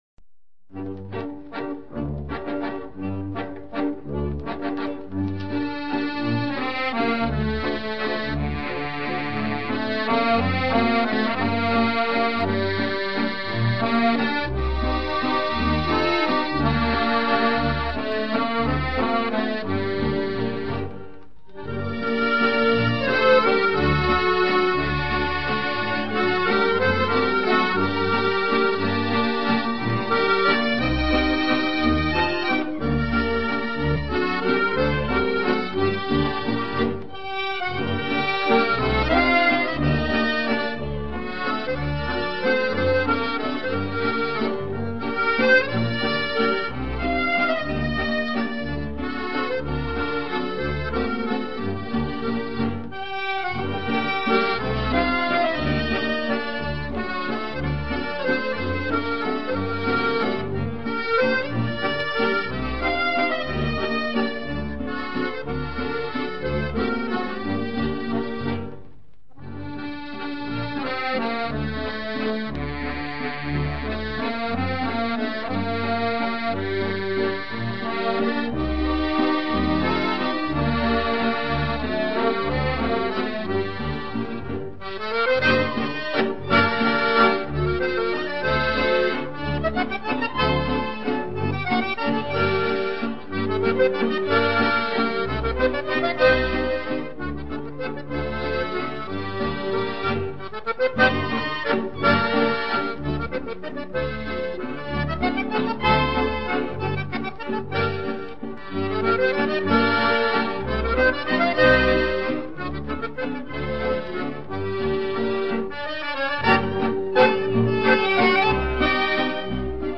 «Дачные грёзы» — вальс военного музыканта и композитора Ильи Алексеевича Шатрова